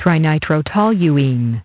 Transcription and pronunciation of the word "trinitrotoluene" in British and American variants.